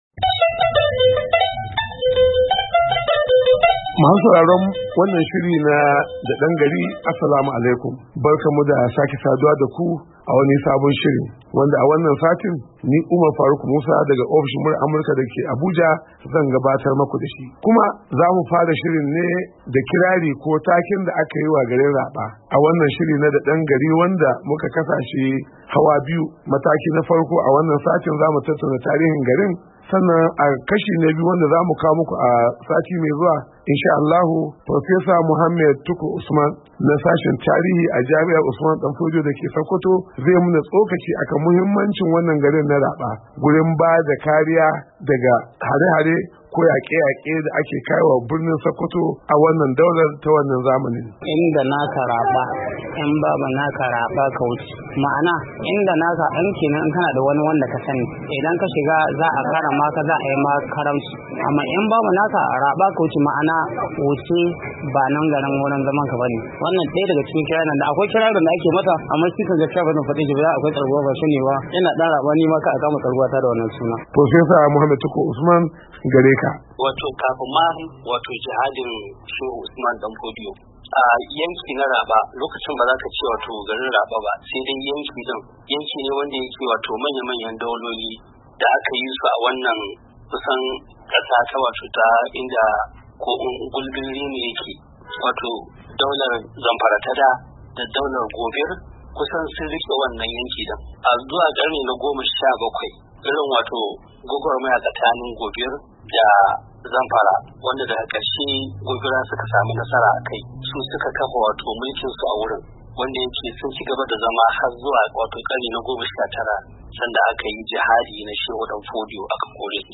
A wata hira